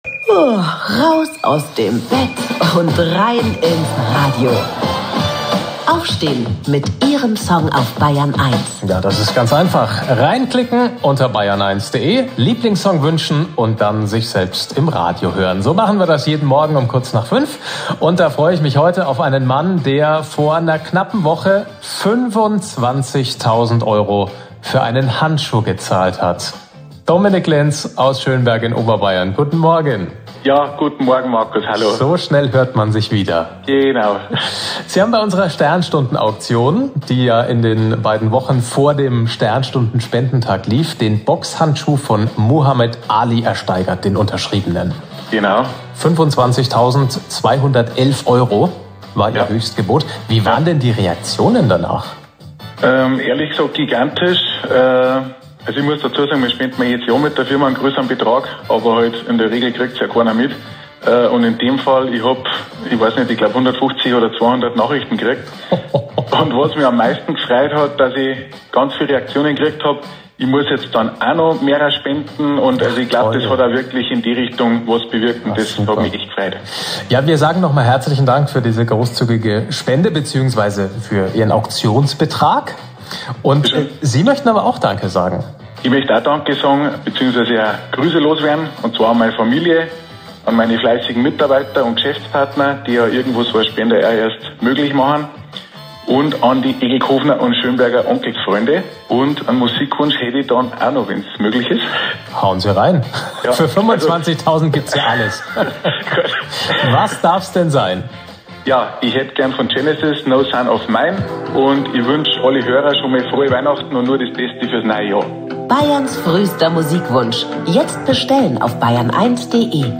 2. Interview mit Bayern1